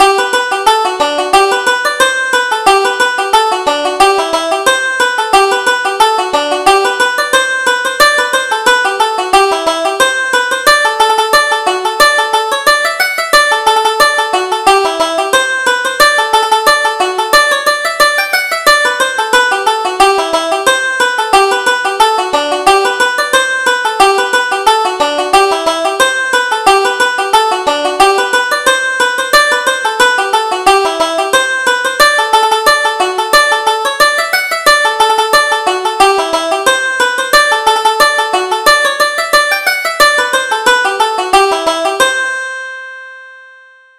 Reel: Boil the Breakfast Early